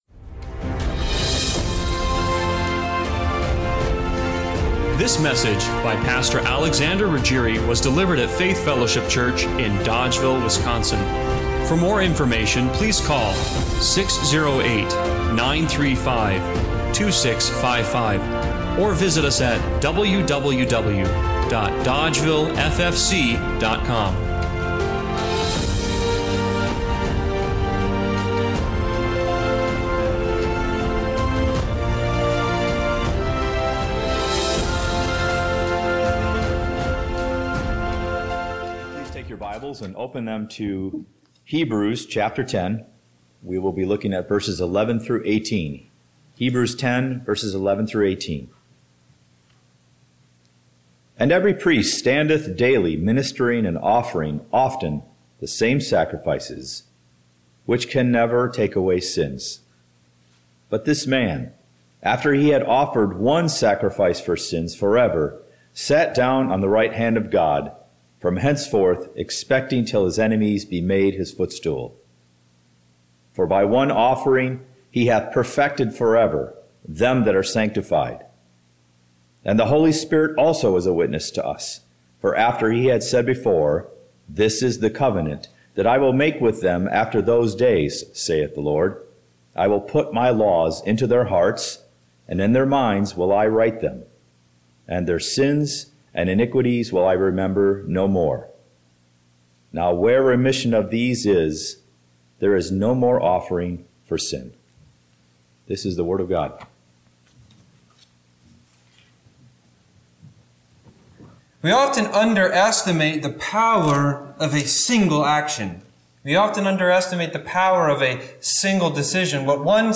Hebrews 10:1-18 Service Type: Sunday Morning Worship What kind of power does one action hold?